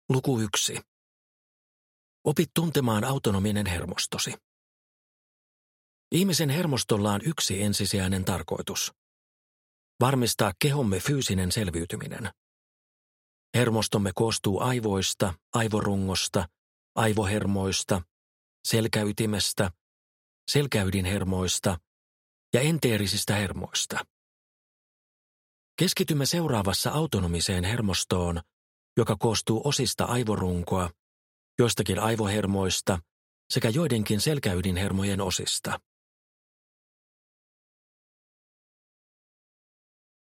Opas vagushermon parantavaan voimaan – Ljudbok